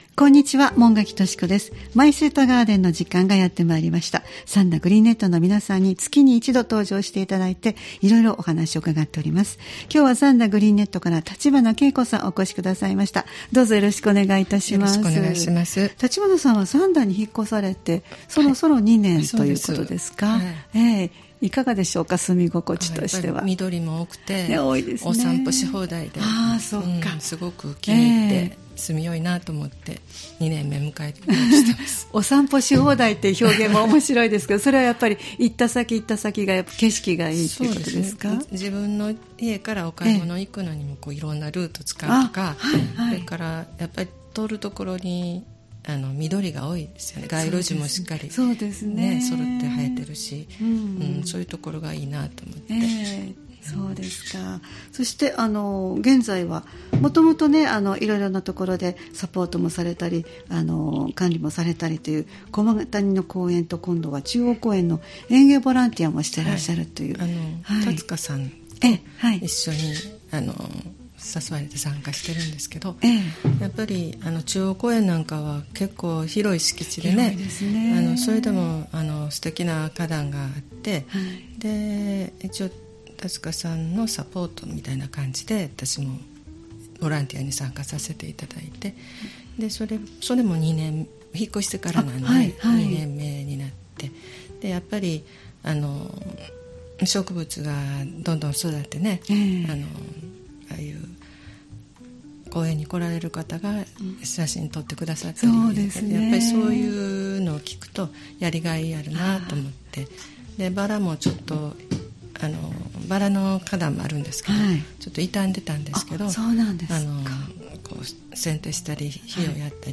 毎月第1火曜日は兵庫県三田市、神戸市北区、西宮市北部でオープンガーデンを開催されている三田グリーンネットの会員の方をスタジオにお迎えしてお庭の様子をお聞きする「マイスイートガーデン」をポッドキャスト配信しています（再生ボタン▶を押すと番組が始まります）